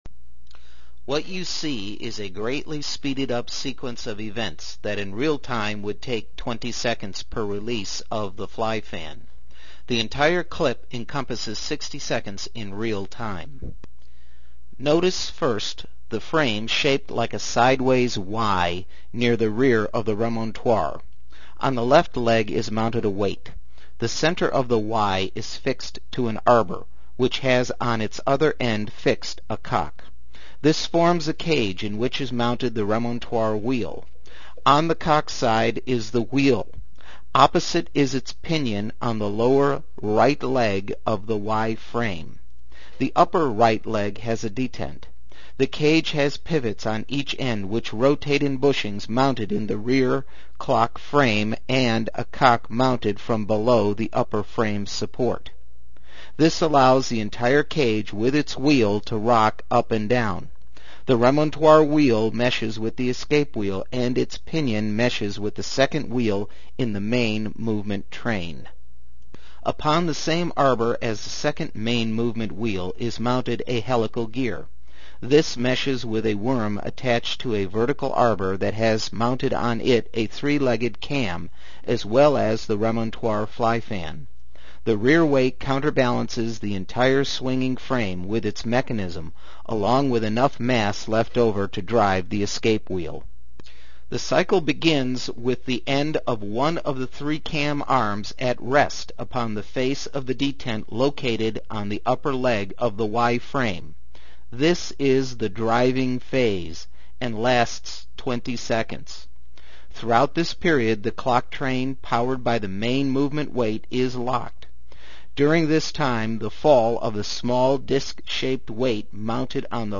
To hear a narration of the above animation right click mouse button on link, choose OPEN LINK IN NEW TAB, click on the new tab and the narration will begin, then click back to this tab to hear the narration as you watch:
Text of voice over narrative below: